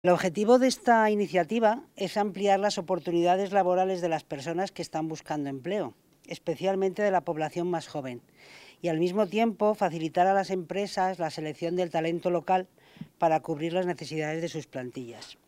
Declaraciones de la delegada provincial Susana Blas